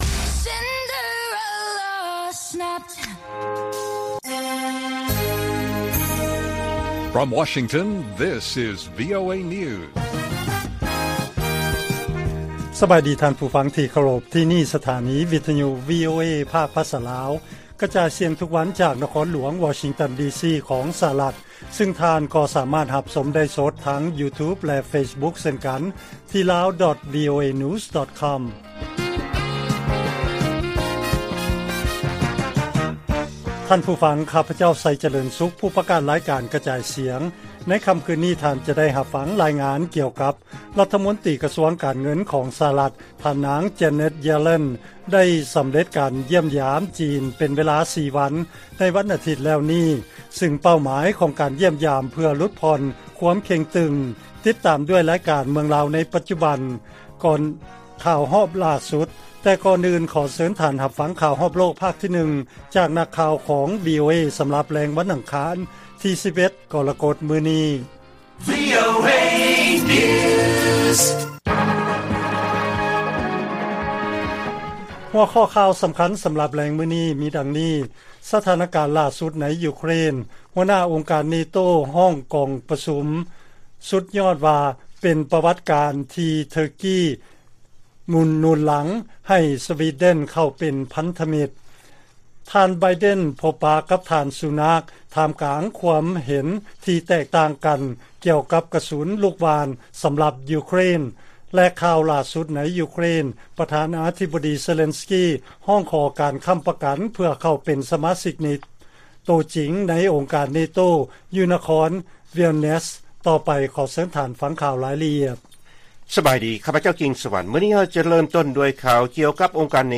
ລາຍການກະຈາຍສຽງຂອງວີໂອເອ ລາວ: ຫົວໜ້າອົງການເນໂຕ ຮ້ອງກອງປະຊຸມສຸດຍອດວ່າ ເປັນປະຫວັດການ ທີ່ເທີກີ ໜູນຫລັງໃຫ້ສະວີເດັນເຂົ້າເປັນພັນທະມິດ